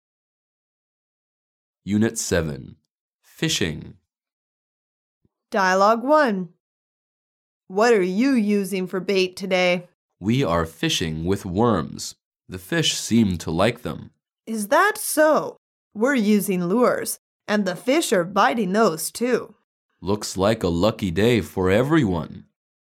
Dialouge 1